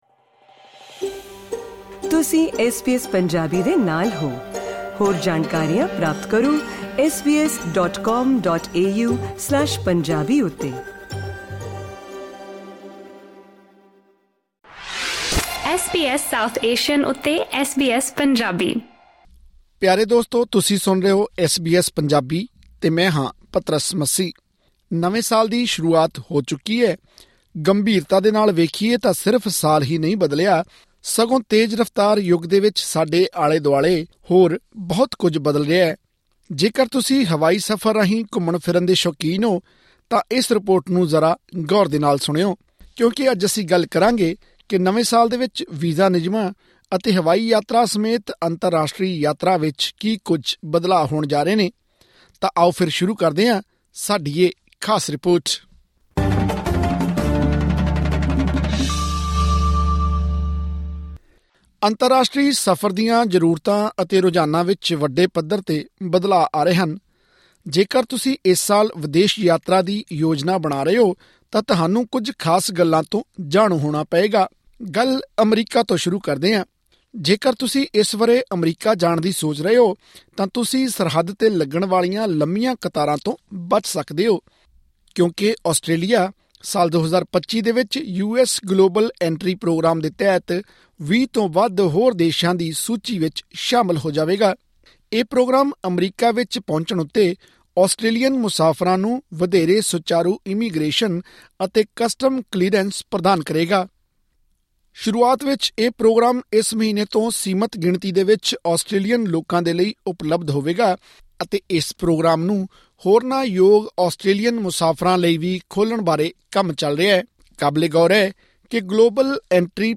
ਆਸਟ੍ਰੇਲੀਆ ਤੋਂ ਅਮਰੀਕਾ, ਯੂਕੇ ਅਤੇ ਹੋਰਨਾਂ ਮੁਲਕਾਂ ਵੱਲ ਜਾਣ ਵਾਲੇ ਅੰਤਰਰਾਸ਼ਟਰੀ ਮੁਸਾਫ਼ਰਾਂ ਲਈ ਕਈ ਨਵੇਂ ਵੀਜ਼ਾ ਨਿਯਮ ਲਾਗੂ ਹੋ ਰਹੇ ਹਨ। ਏਅਰਲਾਈਨਜ਼ ਵੱਲੋਂ ਆਪਣੇ ਕੁਝ ਰੂਟ ਬਦਲੇ ਜਾ ਰਹੇ ਹਨ ਅਤੇ ਕੁਝ ਉਡਾਣਾਂ ਬੰਦ ਵੀ ਕੀਤੀਆਂ ਜਾ ਰਹੀਆਂ ਹਨ। ਇਸੇ ਤਰ੍ਹਾਂ ਘਰੇਲੂ ਤੇ ਅੰਤਰਰਾਸ਼ਟਰੀ ਉਡਾਣਾਂ ਦੀਆਂ ਕੀਮਤਾਂ ਵਿੱਚ ਵੀ ਵਾਧਾ ਹੋਣ ਦੀ ਗੱਲ ਆਖੀ ਜਾ ਰਹੀ ਹੈ। ਹੋਰ ਵੇਰਵੇ ਲਈ ਸੁਣੋ ਇਹ ਰਿਪੋਰਟ